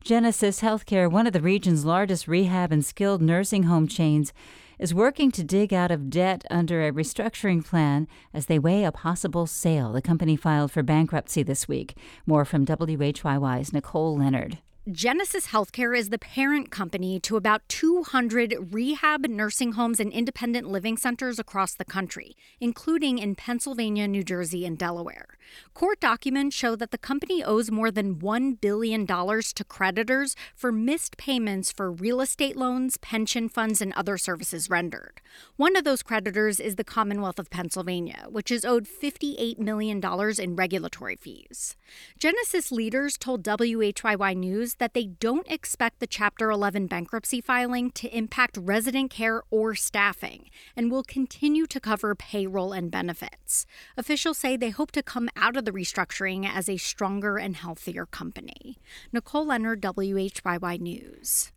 On this episode, we explore viruses — how they affect our bodies, and what happens when they stick around. We hear from a physician who’s treating long-haul COVID-19 patients, and find out from an immunologist how viruses manage to avoid and evade our body’s defense system. We’ll also explore how new research into the after-effects of viruses could benefit many people who are suffering with other little-understood conditions.